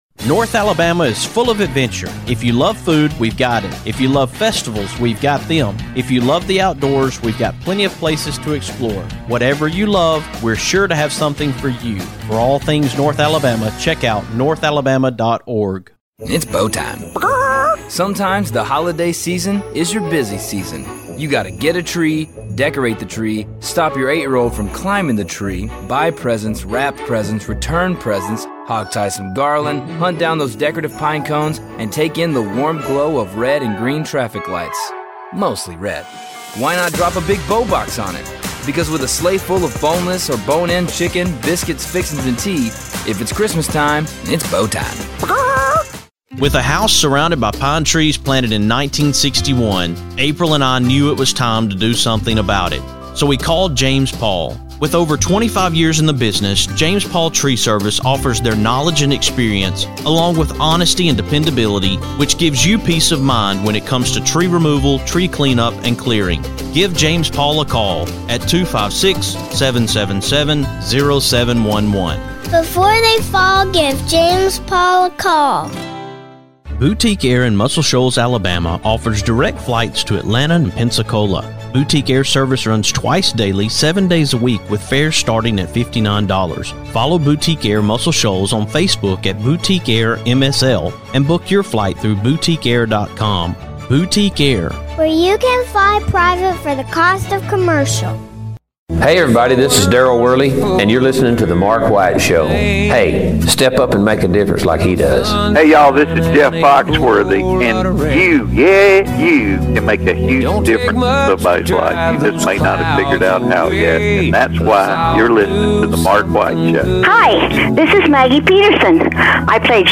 In an effort to support this album, on tonight's show, I’m sharing my interview with BJ where he shared about his love of music as a young person and the early beginnings of his music career.